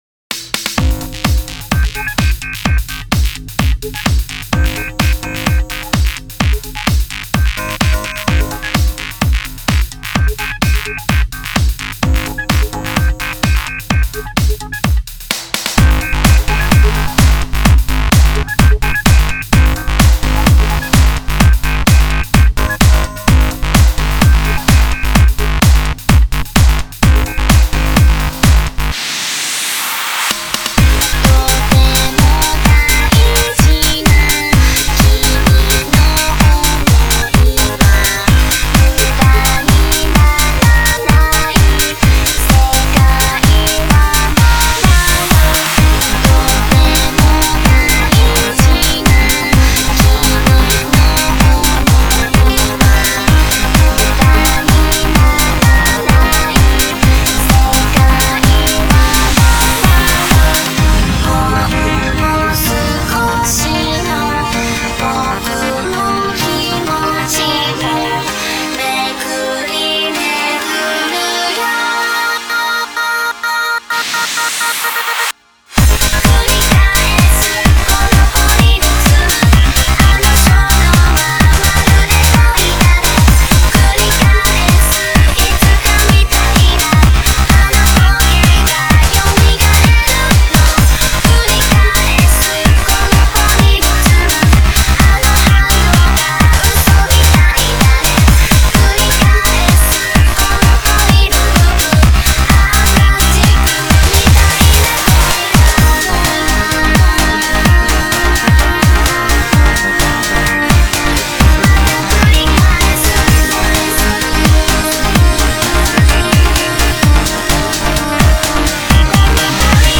Genre(s): Electro